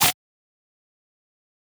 JPGC_one_shot_juicy_foot.wav